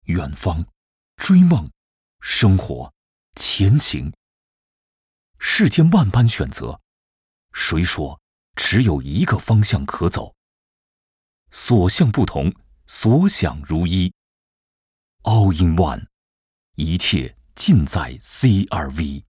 Male
Adult (30-50)
My voice can be friendly, passionate, and engaging. But also can be energetic, cheerful, and believable.
Commercials
All our voice actors have professional broadcast quality recording studios.